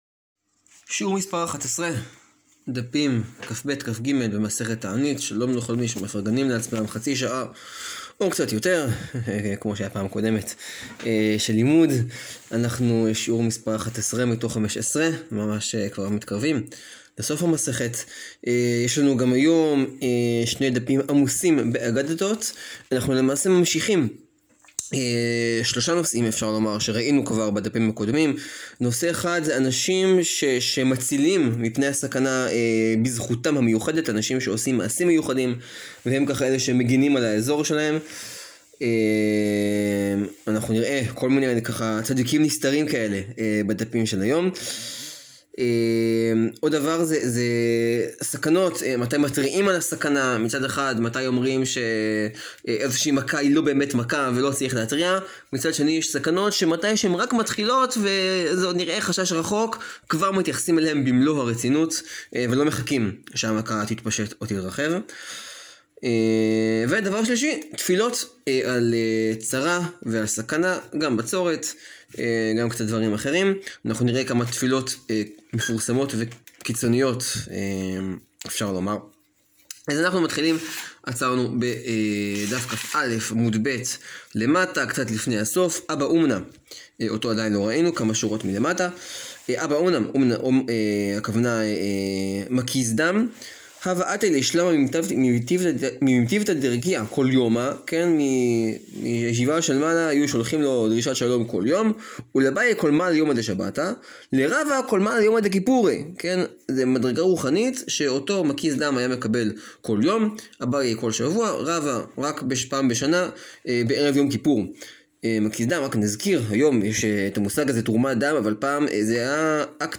שיעור 11 להאזנה: מסכת תענית, דפים כב-כג.